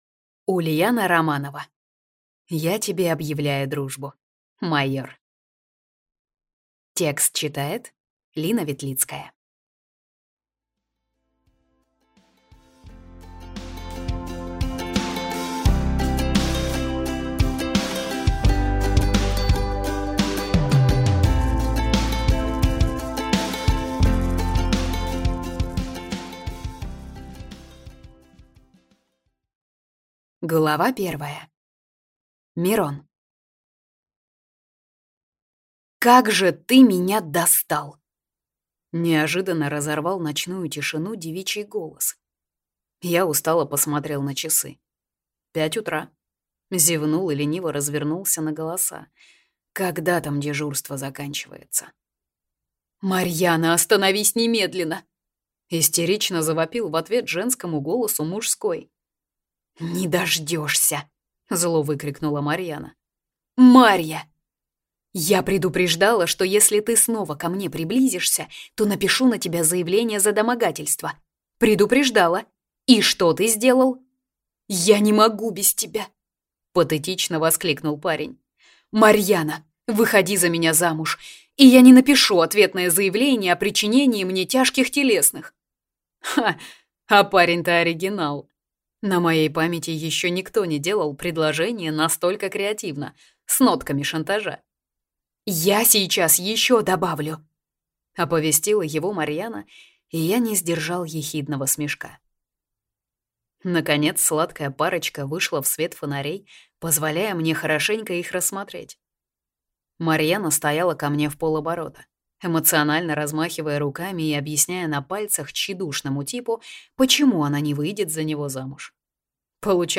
Аудиокнига Я тебе объявляю дружбу, майор | Библиотека аудиокниг